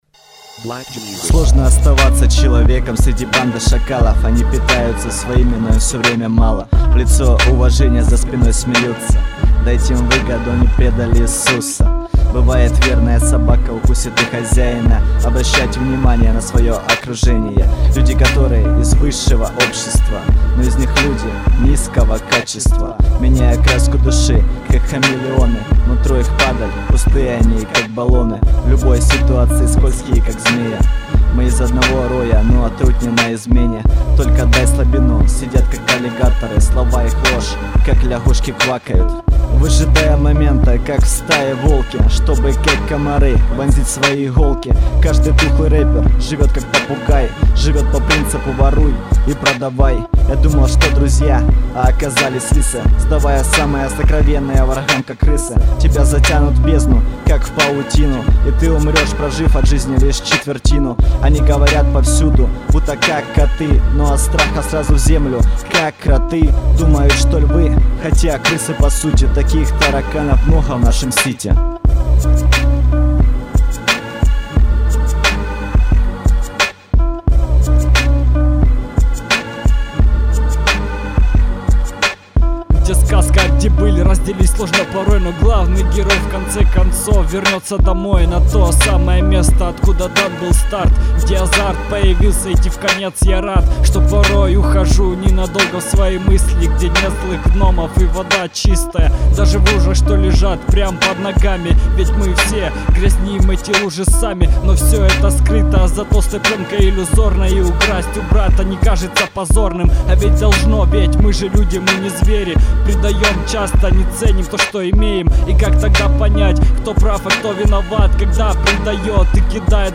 Rap & Hip-hop